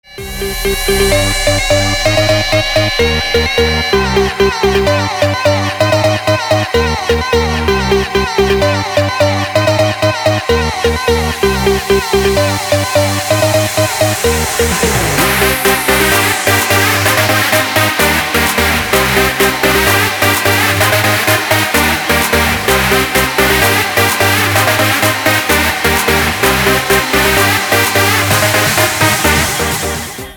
• Качество: 320, Stereo
dance
без слов
club